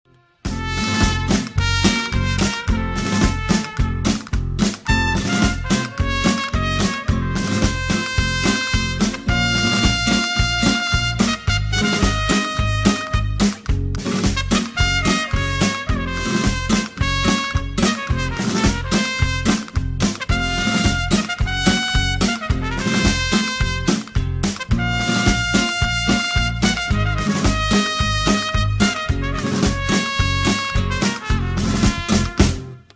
• Качество: 192, Stereo
из мультсериала
пасодобль